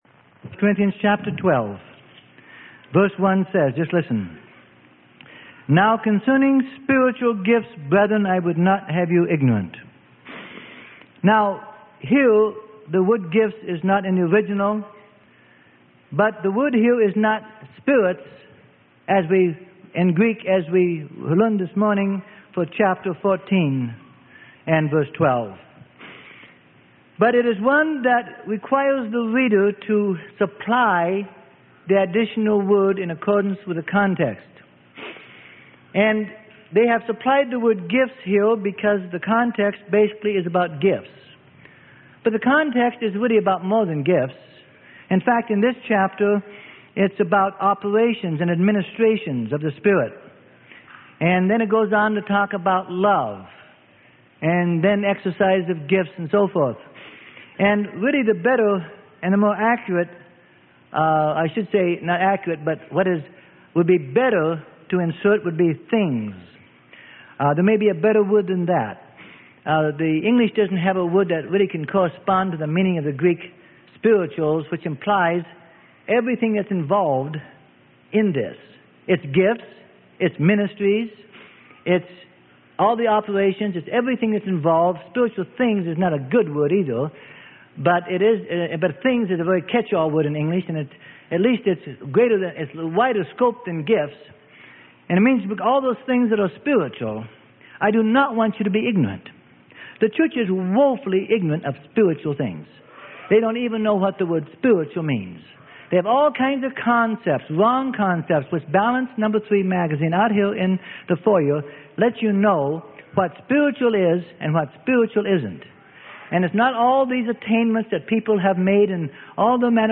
Sermon: Live By the Spirit - Part 4 - Freely Given Online Library